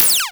kenney_interfacesounds
close_003.ogg